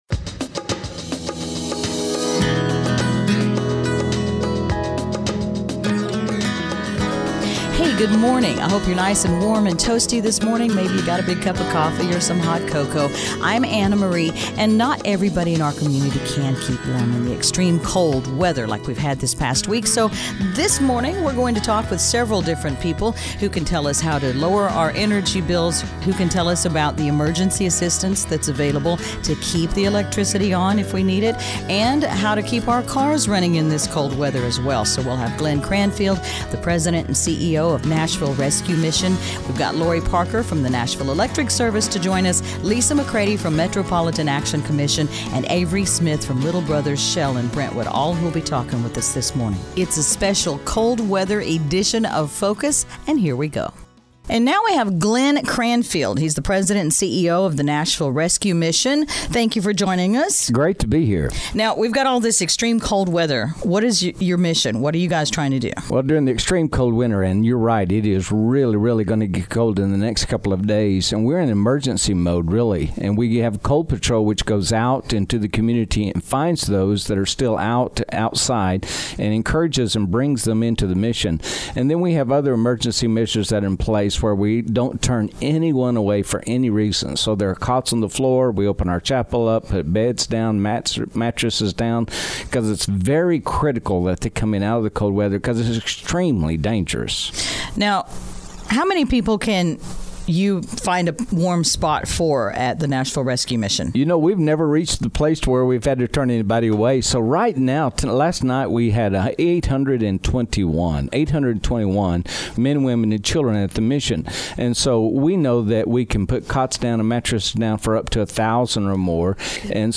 NES recently participated in a special edition of Mix 92.9’s public affairs radio show, Focus, to share tips on how to lower energy bills and make the extreme cold more tolerable.
Additional guests on the show include representatives from the Metro Action Commission, the Nashville Rescue Mission and Little Brothers Shell.